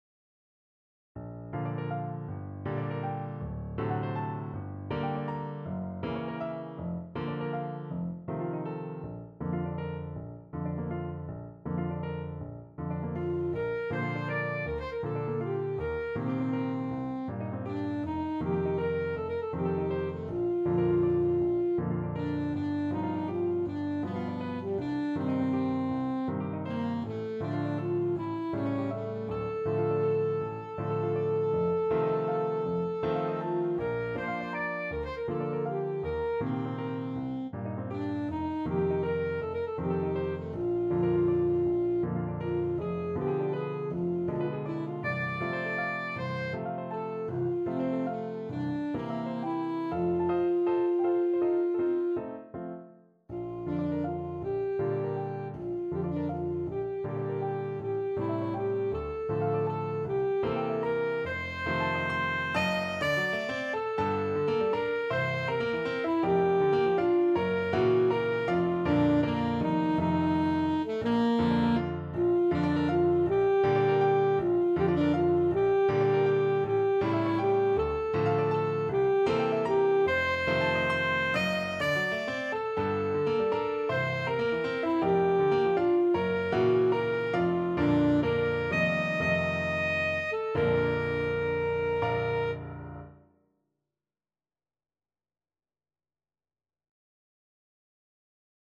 Alto Saxophone
6/8 (View more 6/8 Music)
Classical (View more Classical Saxophone Music)